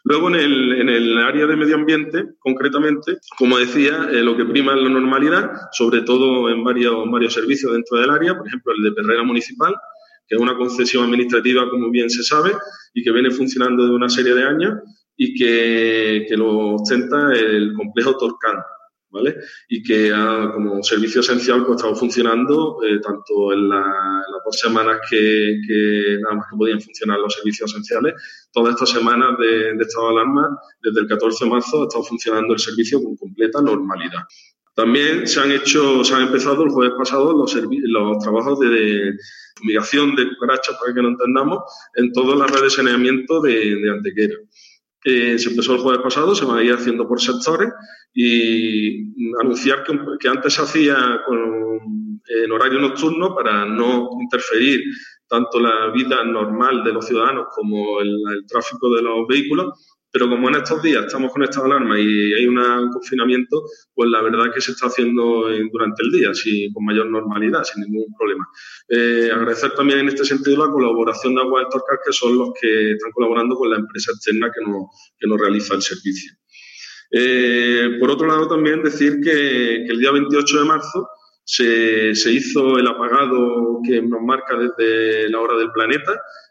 El alcalde de Antequera, Manolo Barón, y el concejal Juan Álvarez han desarrollado en la mañana de hoy una rueda de prensa telemática para la valoración del trabajo que se lleva realizando durante el Estado de Alarma en las áreas municipales de Anejos, Medio Ambiente y Electricidad.
Cortes de voz